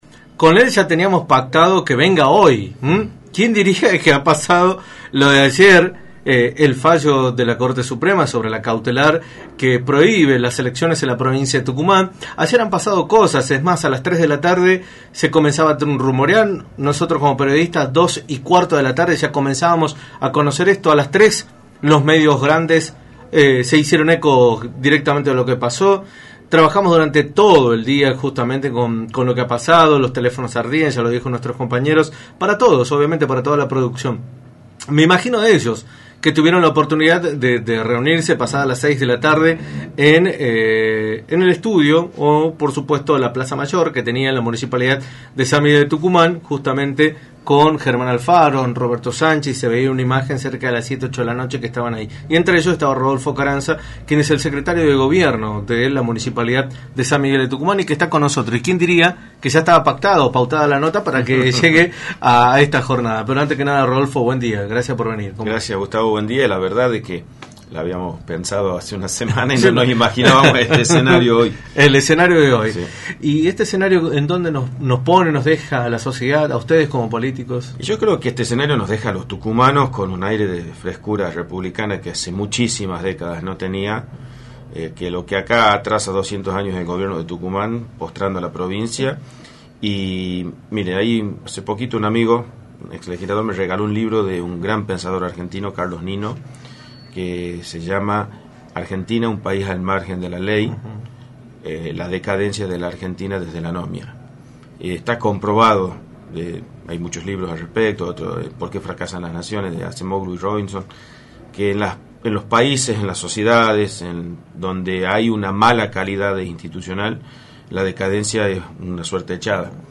Rodolfo Ocaraznza, Secretario de Gobierno de la Municipalidad de San Miguel de Tucumán y candidato a Legislador por el sector Oeste, visitó los estudios de Radio del Plata Tucumán, por la 93.9, para analizar los consecuencias del fallo de la Corte Suprema de Justicia de la Nación sobre la suspensión y postergación de las elecciones provinciales que estaban previstas para el domingo.